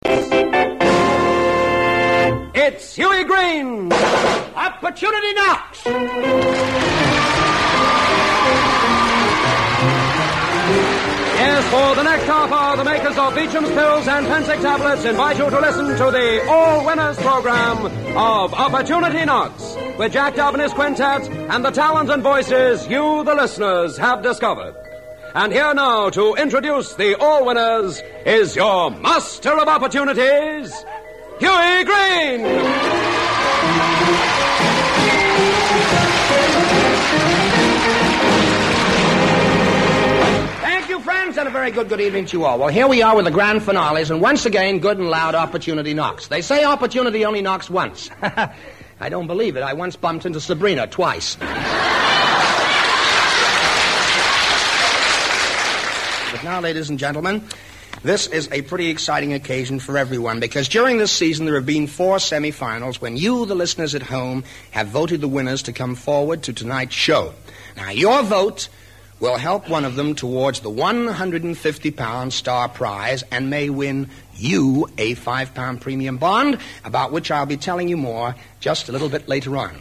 Its host for many years was Hughie Green, a chap with more than a passing interest in commercial radio. Here he is in full flow on Luxembourg’s ‘Opportunity Knocks’.